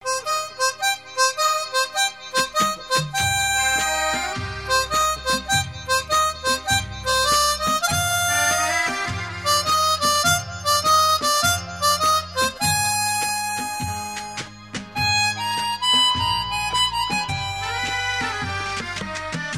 • Bollywood Ringtones